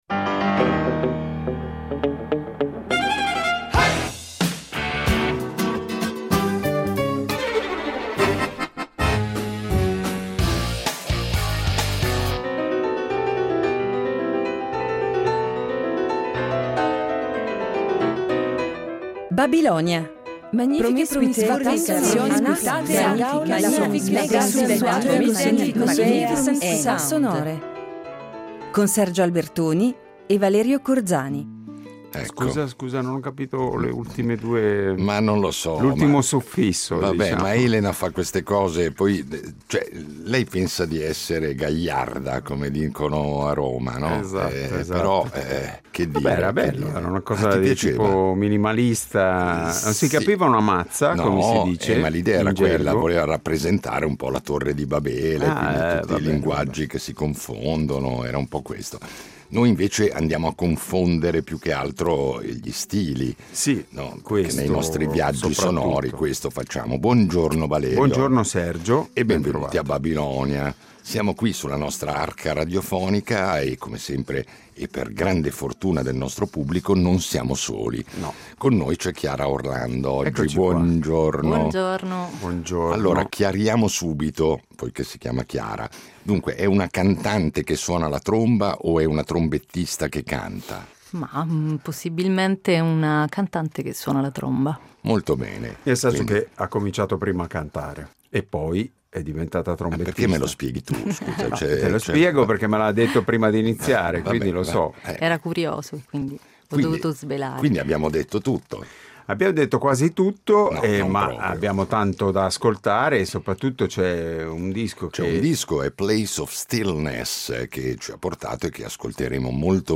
Ecco perché ci sembra doveroso recuperarlo, di tanto in tanto, riunendolo in puntate particolarissime perché svincolate dall’abituale scansione di rubriche e chiacchierate con gli ospiti. Itinerari ancor più sorprendenti, se possibile, che accumulano in modo sfrenato i balzi temporali e di genere che caratterizzano da sempre le scalette di Babilonia .